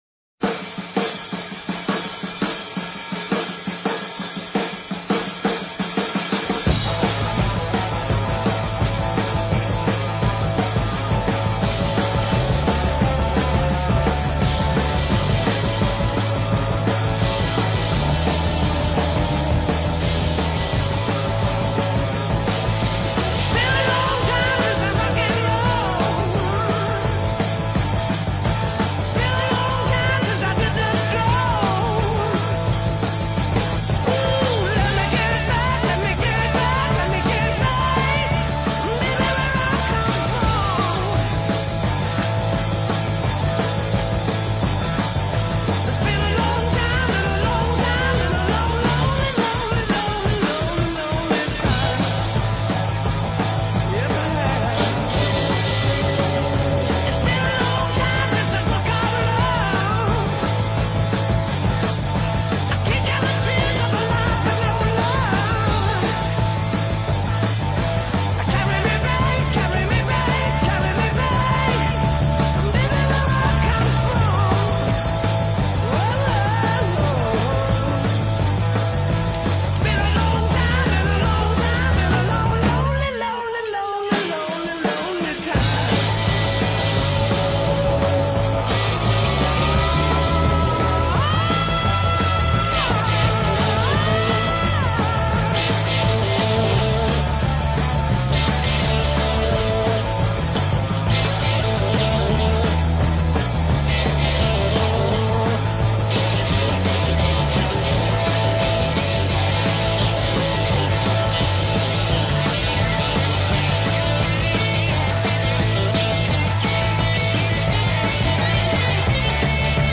LA MÚSICA HEAVY.